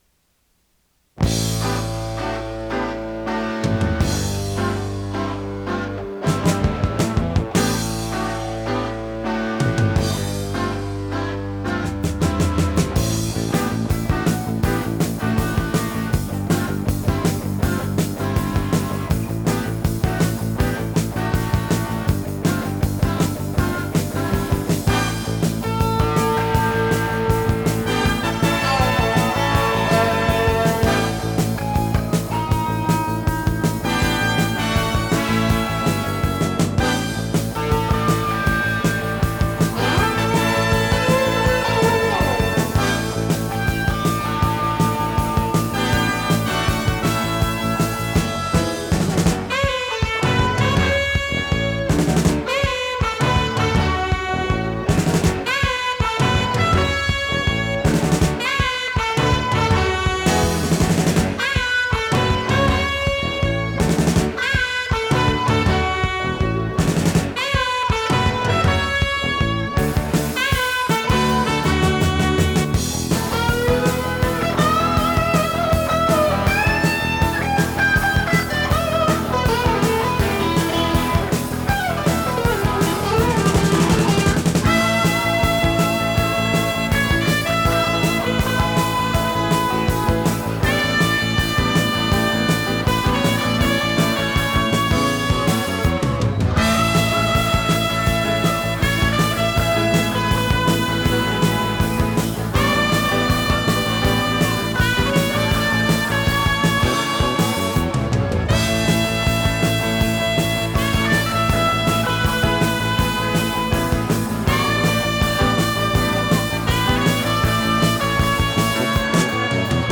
ノイズリダクションOFF
【フュージョン・ロック】★他機で録音したテープ　録音デッキ：TEAC C-3 　48kHz-24bit 容量34.2MB